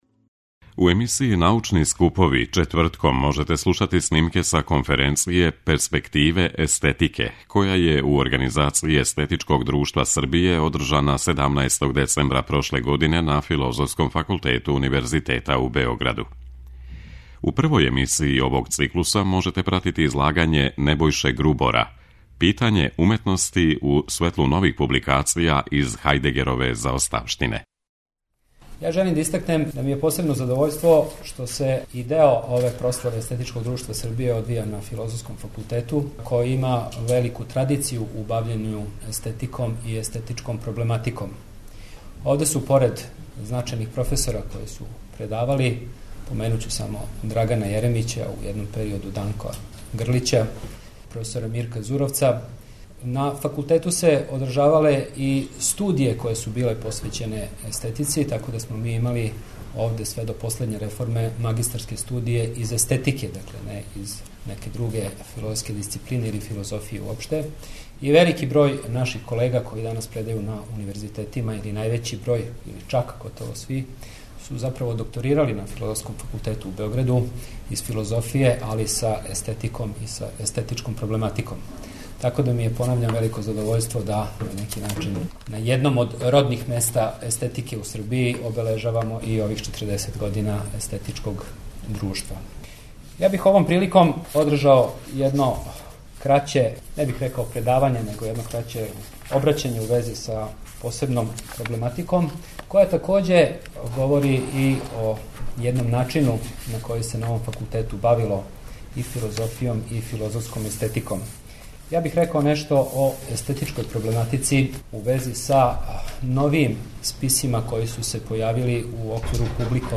У емисији НАУЧНИ СКУПОВИ четвртком можете слушати снимке са конференције ПЕРСПЕКТИВЕ ЕСТЕТИКЕ која је, у организацији Естетичког друштва Србије, одржана 17. децембра на Филозофском факултету Универзитета у Београду.